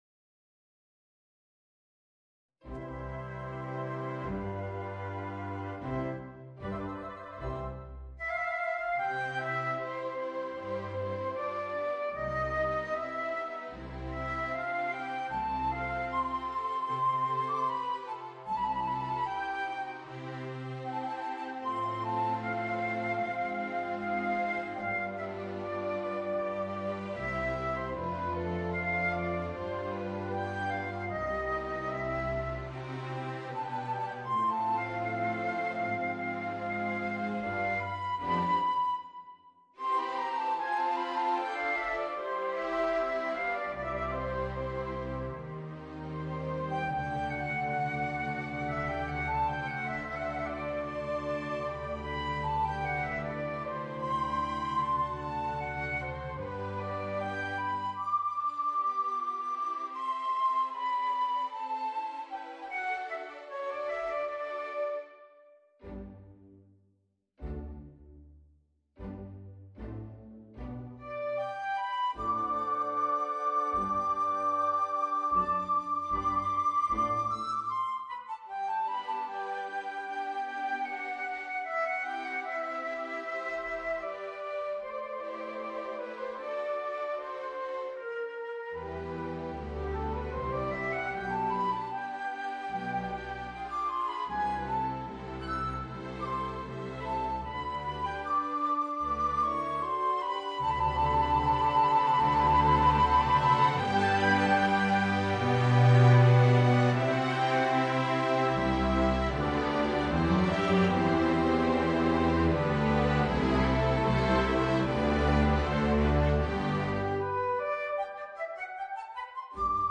Voicing: Bassoon and Orchestra